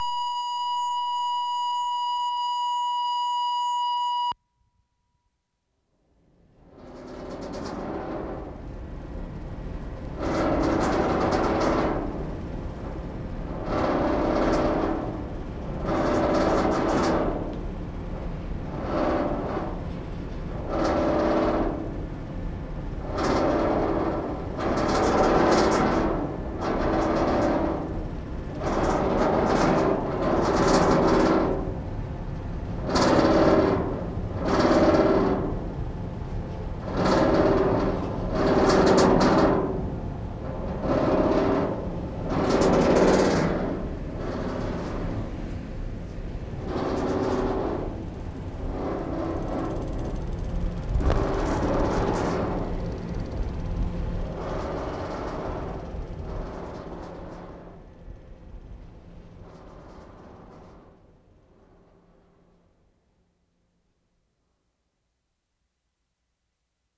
VANCOUVER ISLAND March 16, 1973
ON THE FERRY, PRINCESS OF VANCOUVER, rattling air vent 0'55"
1. Incredible noise, it rattles intermittently, sound reverberates inside duct. Quite interesting.